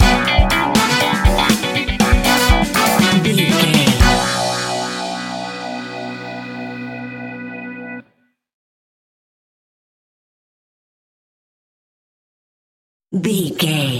Aeolian/Minor
groovy
uplifting
bouncy
smooth
drums
electric guitar
bass guitar
horns
funky house
electronic funk
upbeat
synth leads
Synth pads
synth bass